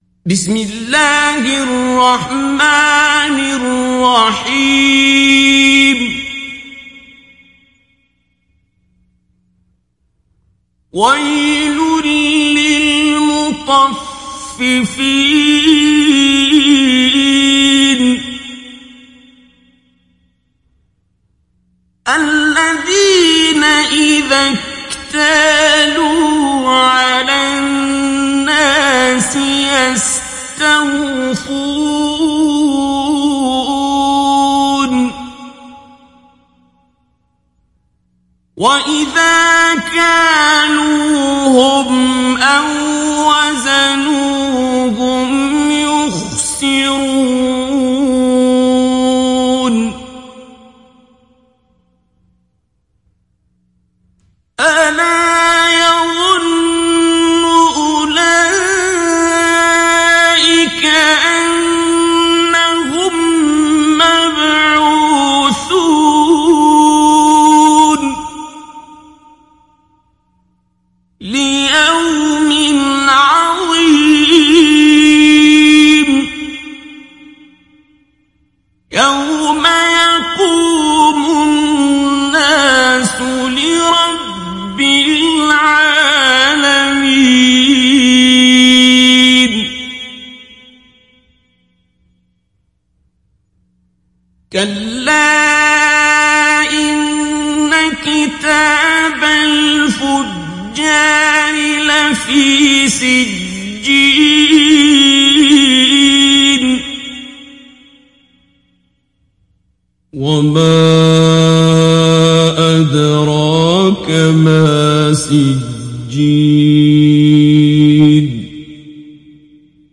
Télécharger Sourate Al Mutaffifin Abdul Basit Abd Alsamad Mujawwad